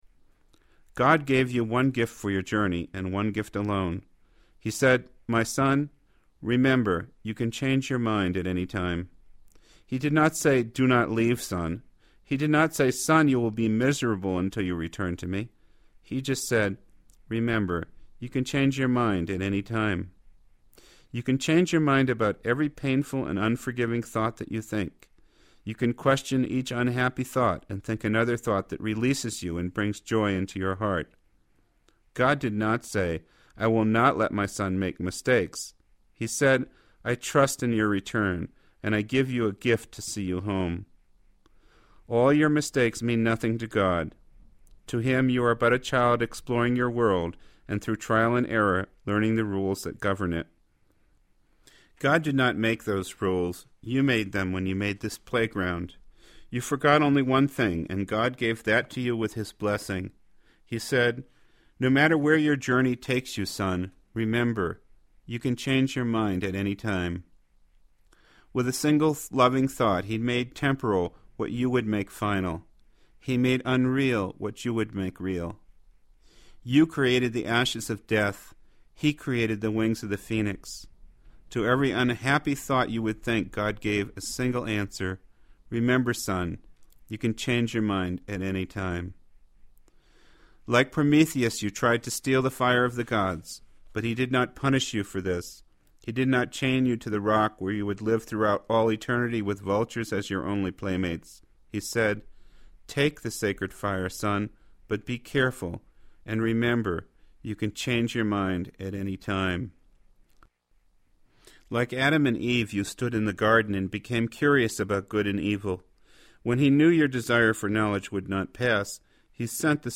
reading from his Christ Mind titles.